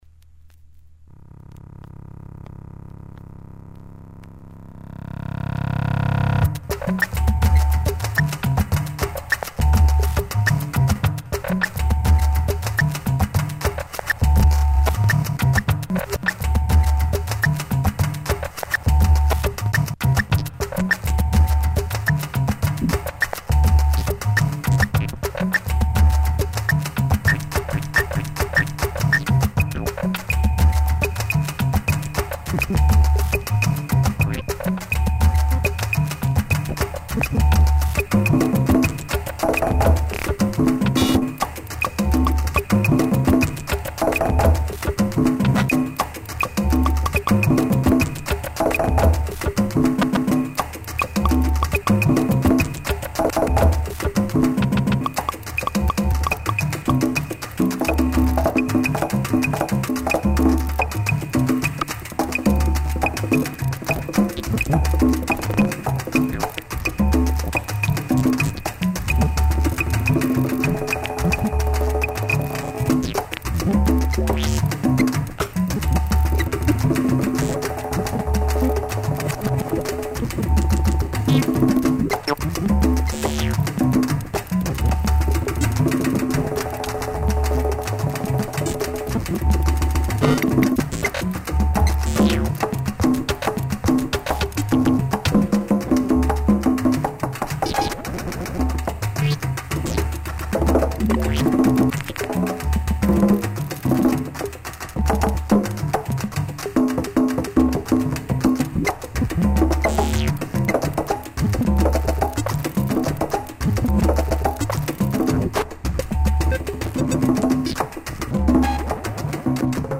2008 Electro Latino